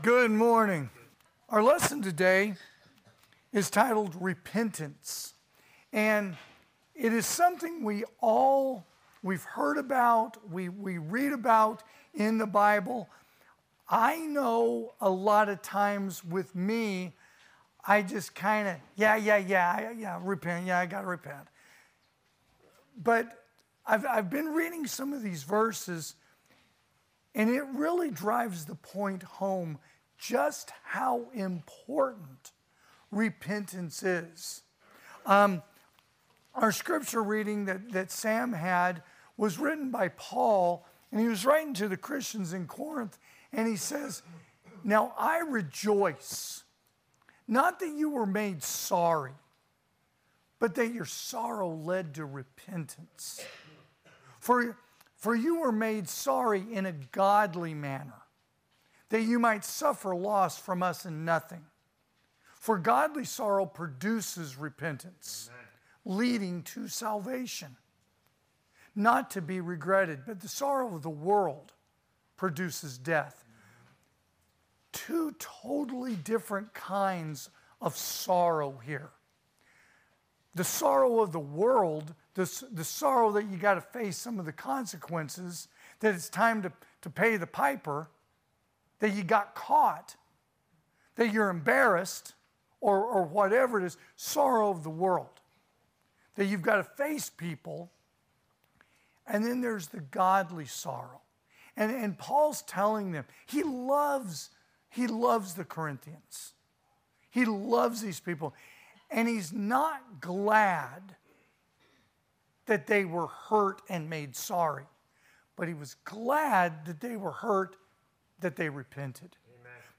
2024 (AM Worship) "Repentance"